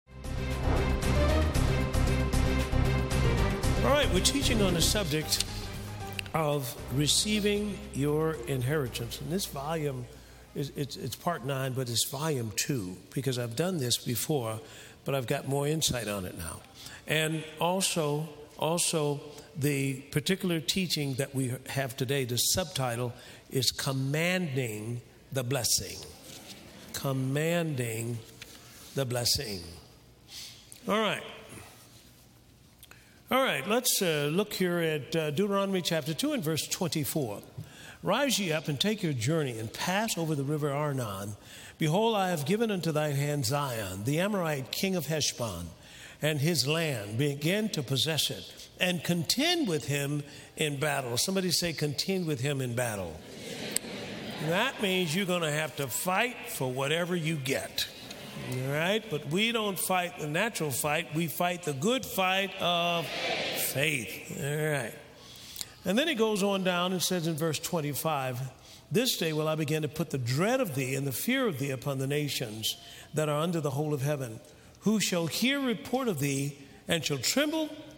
(4 Teachings) Did you know God has commanded The Blessing on your life?